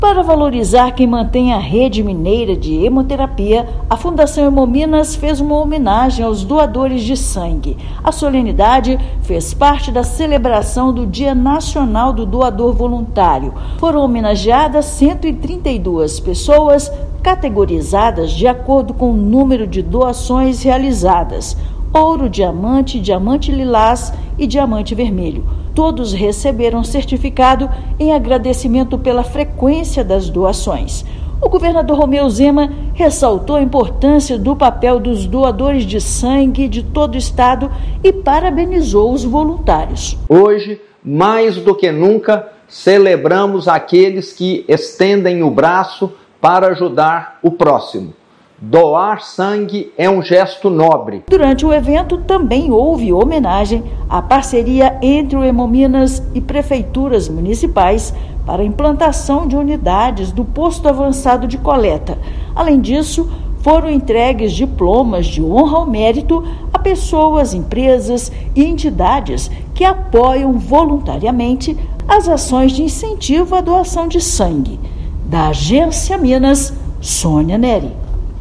Evento reconheceu 132 doadores de sangue e parceiros que mantêm os estoques estáveis e garantem atendimento seguro no estado. Ouça matéria de rádio.